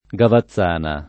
[ g ava ZZ# na ]